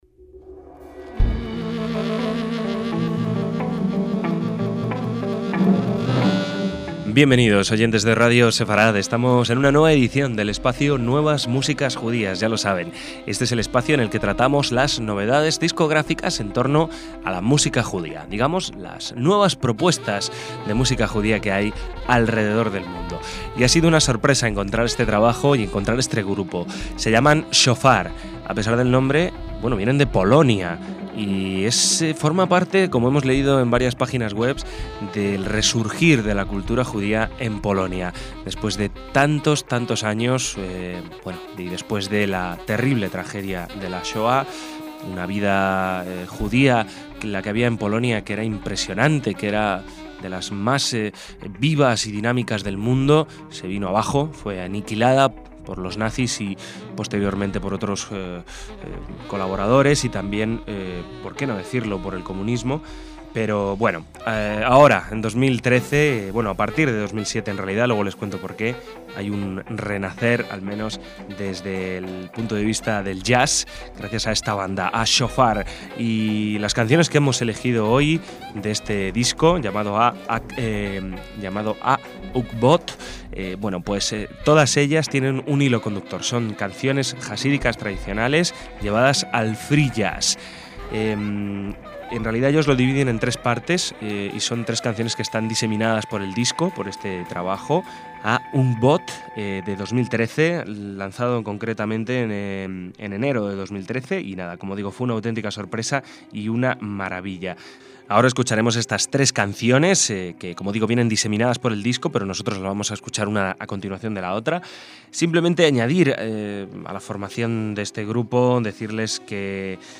trío polaco de free jazz
guitarra eléctrica
saxo alto y clarinete bajo
batería